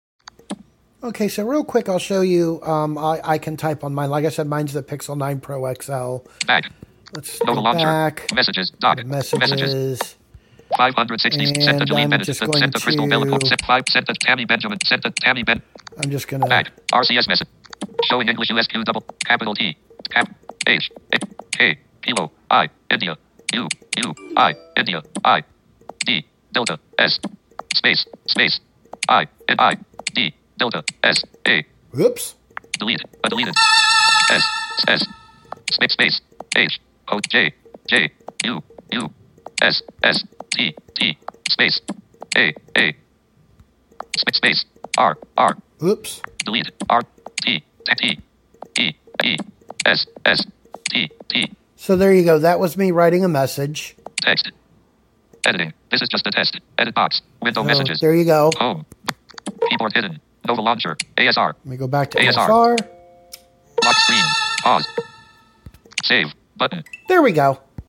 here is an example from my phone of me typing.